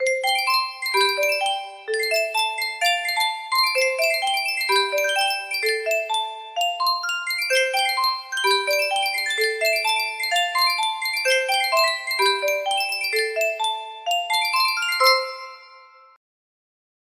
Yunsheng Music Box - Vivaldi Juditha triumphans 5931 music box melody
Full range 60